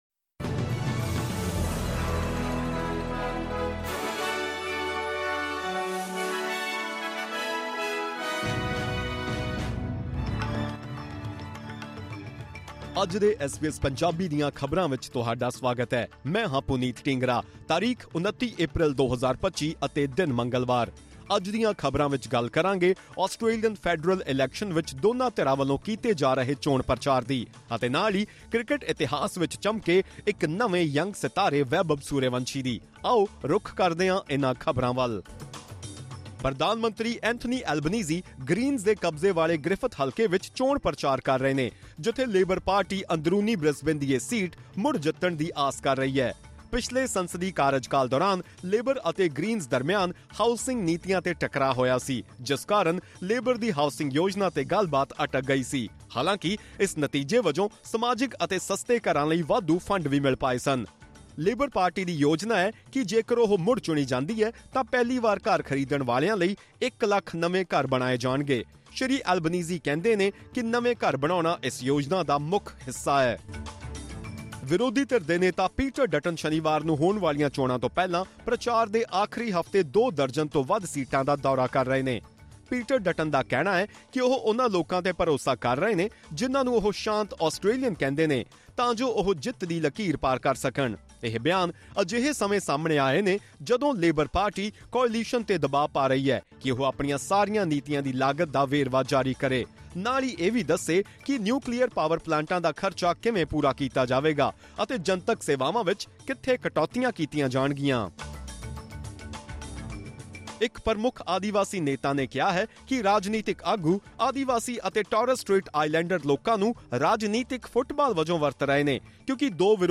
ਖਬਰਨਾਮਾ: ਚੋਣਾਂ ਦੇ ਆਖਰੀ ਹਫ਼ਤੇ ਰਾਜਨੀਤਿਕ ਪਾਰਟੀਆਂ ਵੱਲੋਂ ਪ੍ਰਚਾਰ ਜ਼ੋਰਾਂ 'ਤੇ